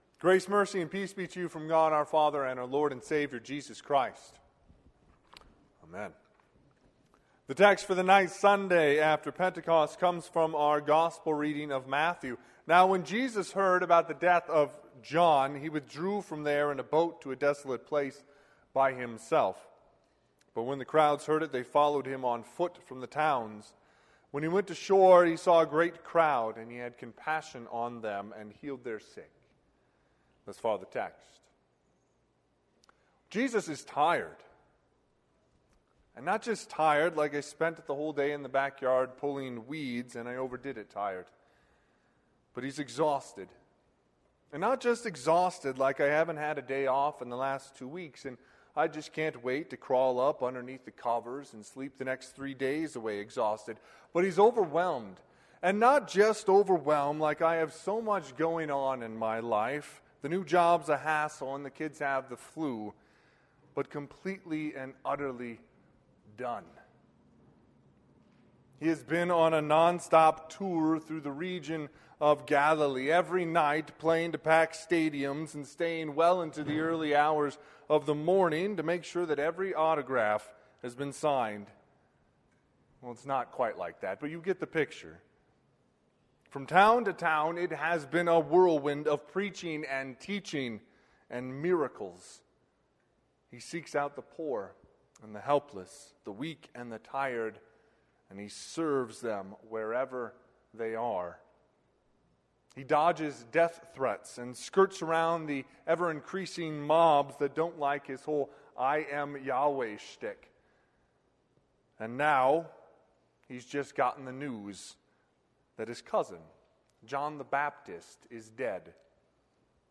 Sermon - 8/2/2020 - Wheat Ridge Lutheran Church, Wheat Ridge, Colorado